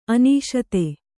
♪ anīśate